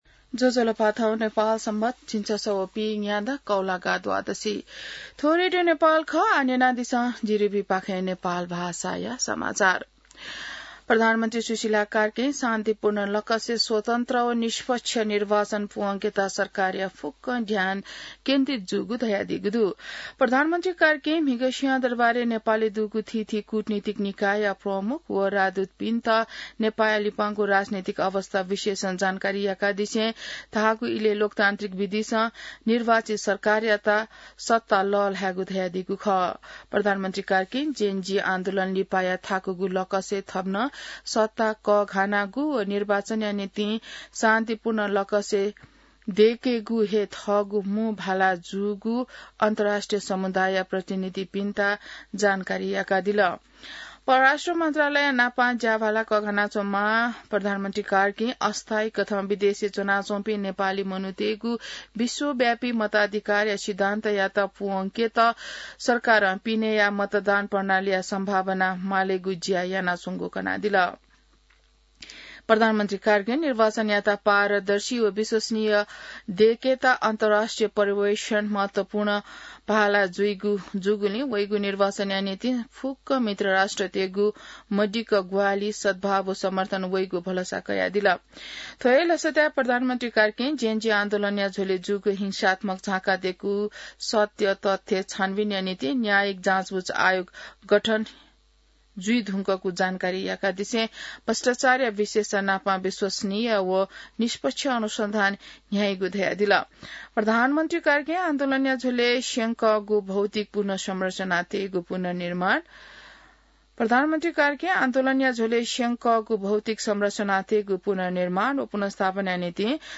नेपाल भाषामा समाचार : १ कार्तिक , २०८२